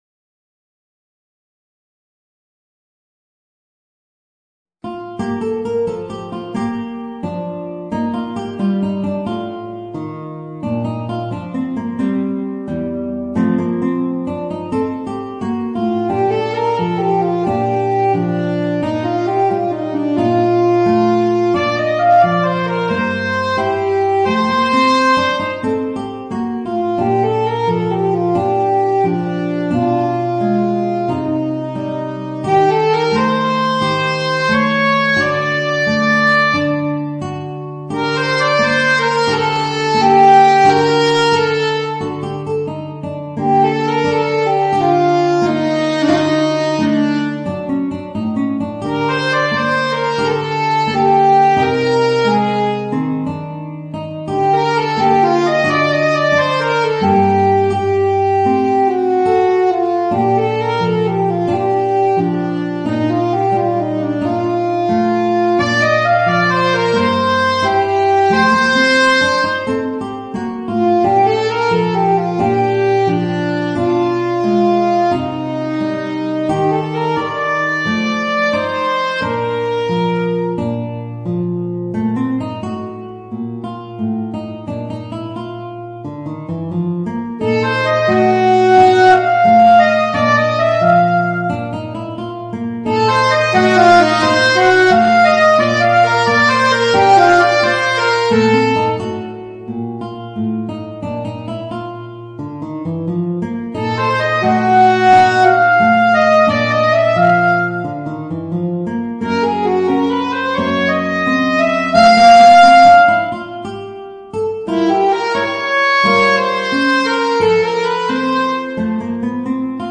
Voicing: Alto Saxophone and Guitar